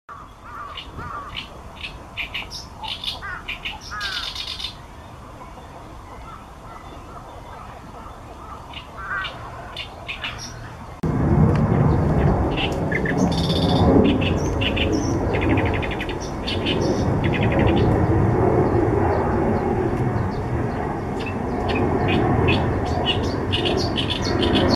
オオヨシキリ　2016-05-08　IMG_4701　　動画　MVI_4714-68.flv　00:24　前半 5/8、後半 5/12 同一場所
同左声のみ　MVI_4714-68.mp3